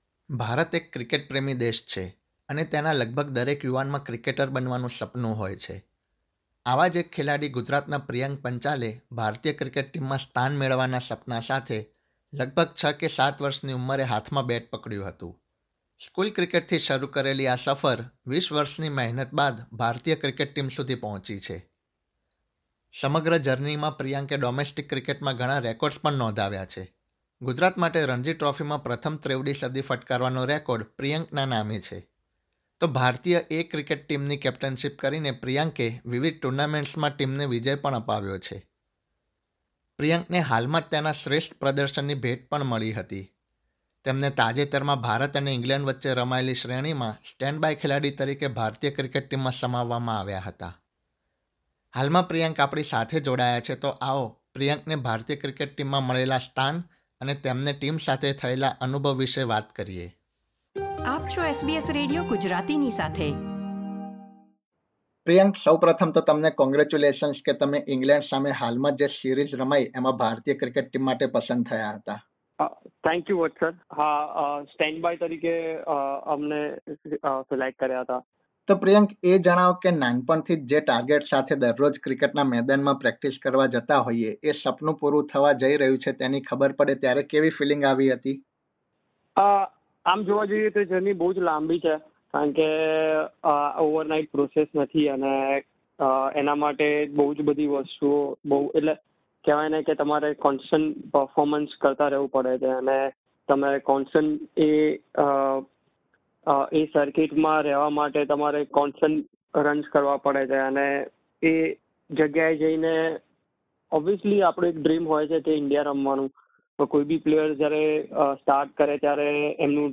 Indian cricketer Priyank Panchal has been in a consistent form in first-class and domestic cricket for many years, which earned him a place as a stand-by player in the Indian team in the recently concluded Test series against England. Priyank spoke to SBS Gujarati about his experience with the senior players of the Indian team and his upcoming goals.